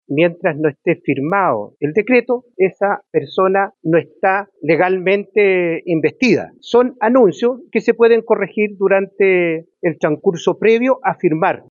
Quien defendió los nombramientos fue el diputado de la UDI, Sergio Bobadilla. Afirmó que siempre se puede mejorar la gestión, revirtiendo nombramientos que aún no estaban seguros.